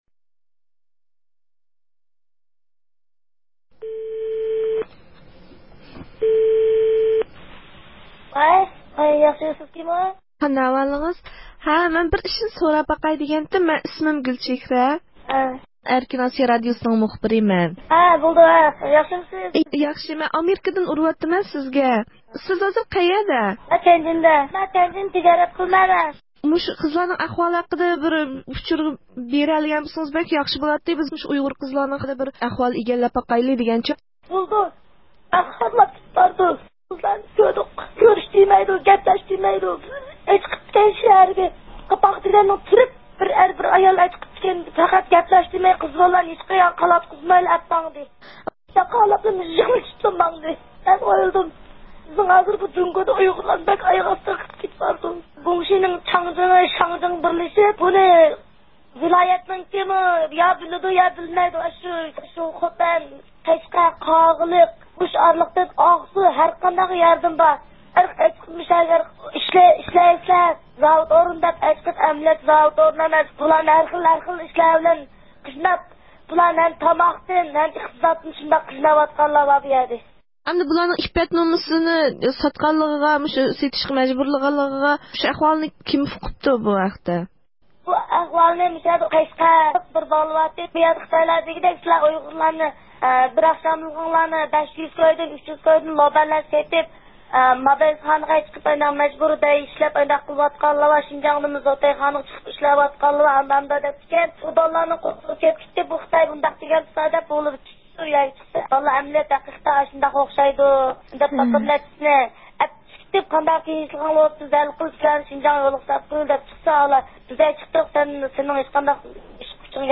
تيەنجىندە سودا ئىشلىرى بىلەن شۇغۇللىنىۋاتقان بىر ئايال سودىگەردىن بۇ ھەقتە مەلۇماتلارغا ئىگە بولدى